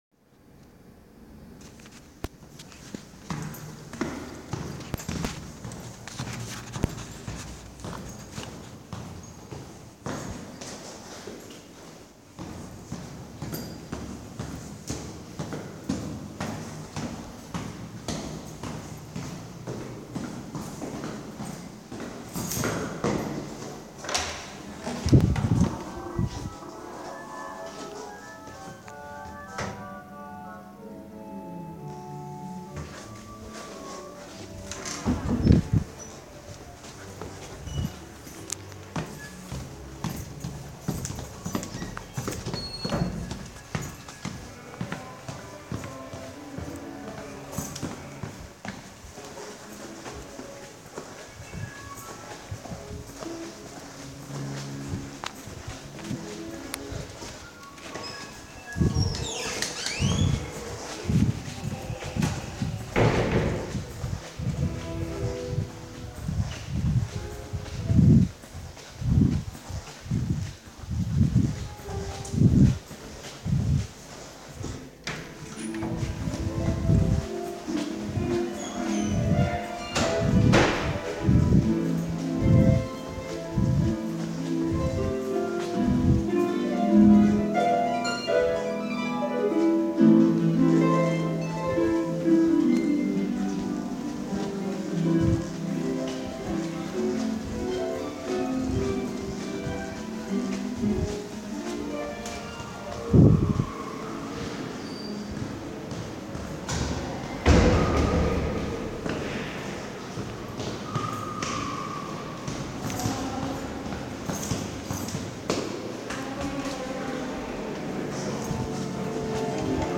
A collage of a college, roaming through the Royal College of Music